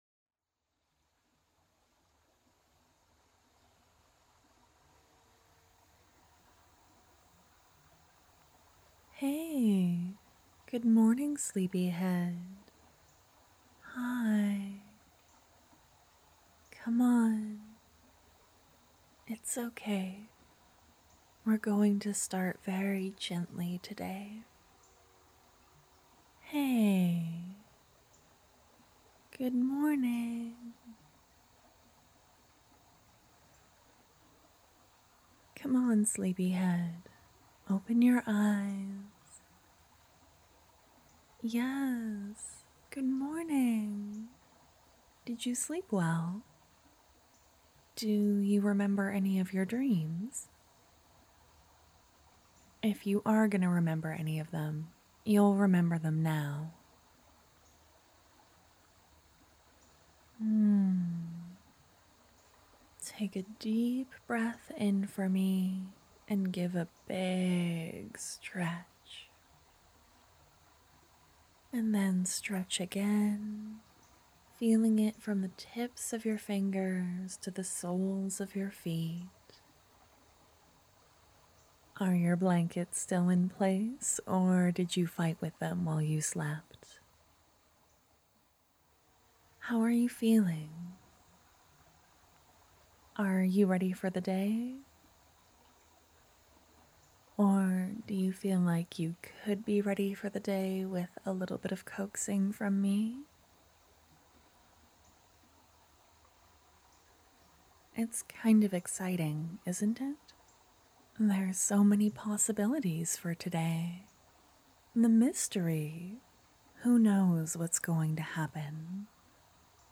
It's a sweet girlfriend good morning with some nature sounds.